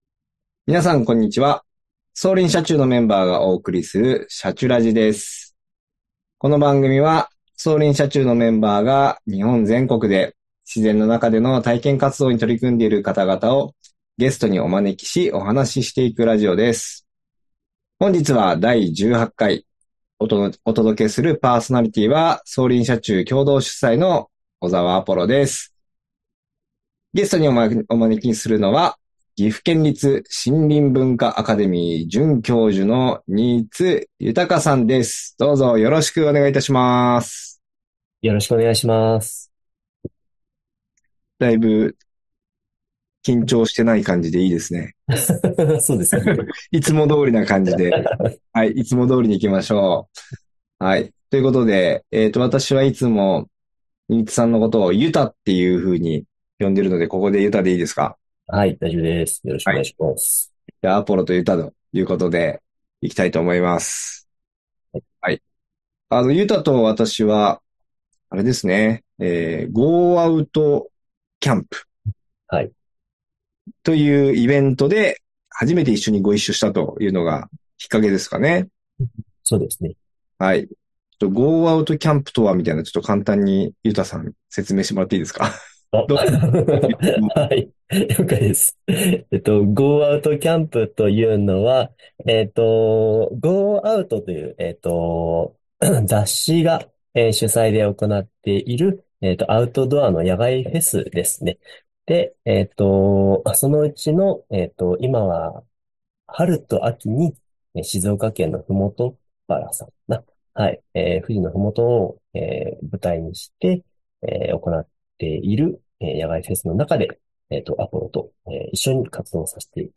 「シャチュラジ」この番組は、走林社中のメンバーが日本全国で自然の中での体験活動に取り組んでいる方々をゲストにお招きし、お話ししていくラジオです。
途中siriも登場するというハプニングを乗り越え楽しいひとときでした。